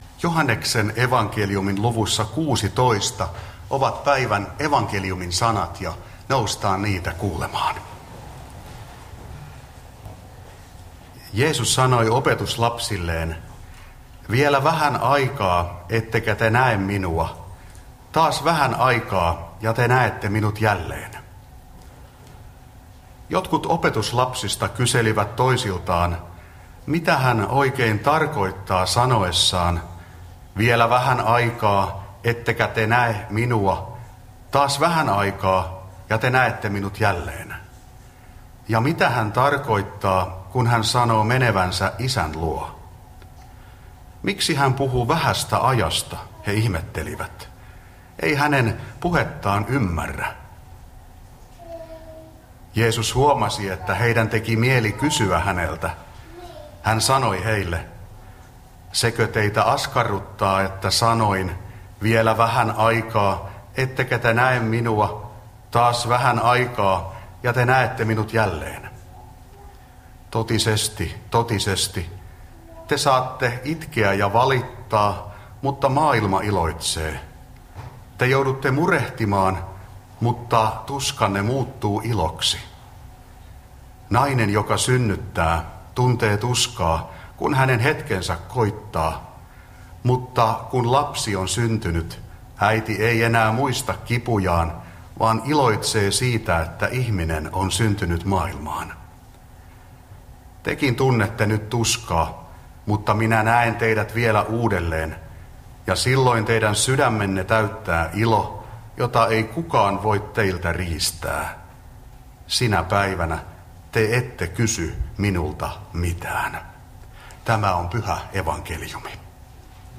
saarna Karkussa 3. sunnuntaina pääsäisestä Tekstinä Joh. 16:16–23